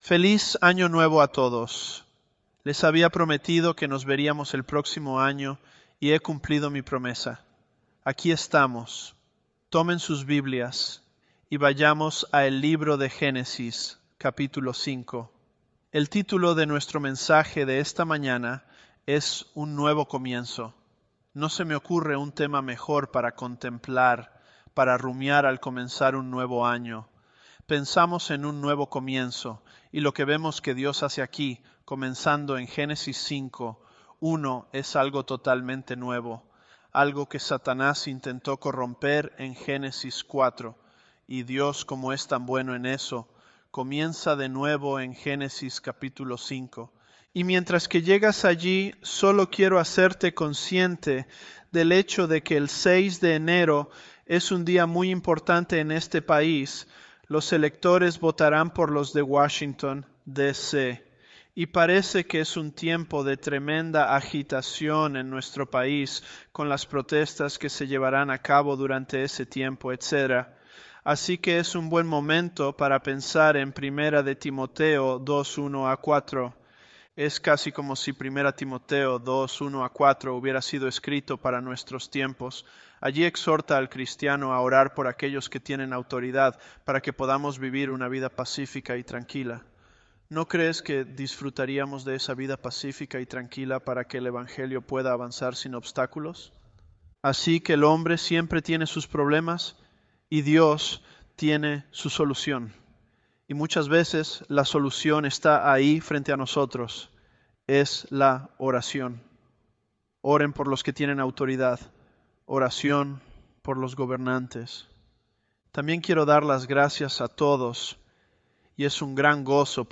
Sermons
ElevenLabs_Genesis-Spanish020.mp3